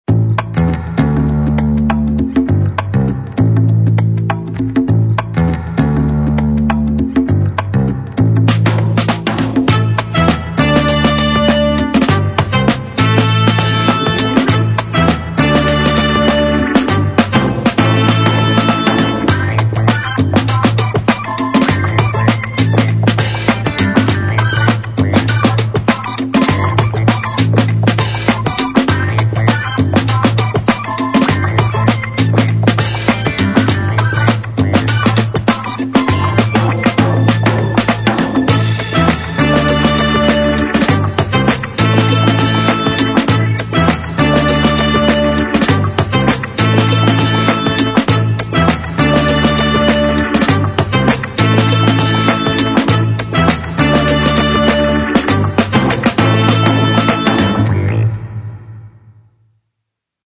描述：强大而绝妙的放克音轨与电影，抢劫电影的感觉就像在海洋的十一，抢夺和更多。